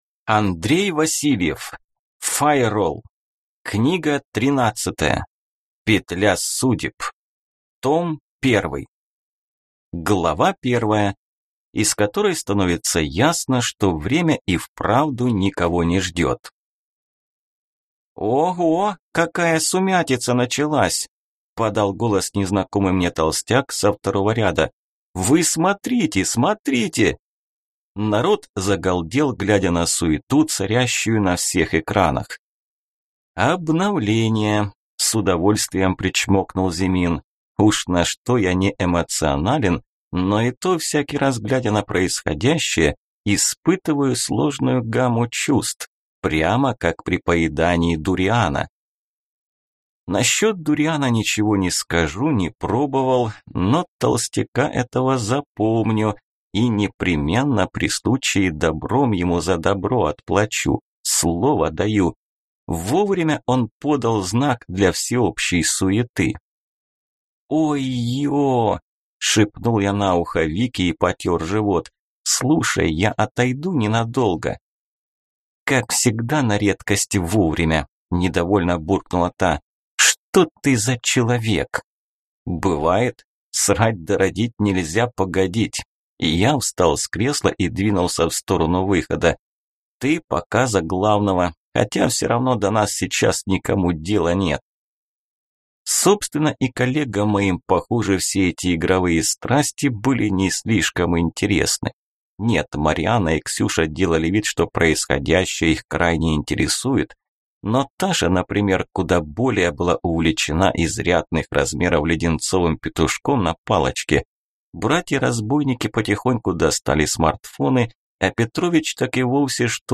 Аудиокнига Файролл. Петля судеб. Том 1 | Библиотека аудиокниг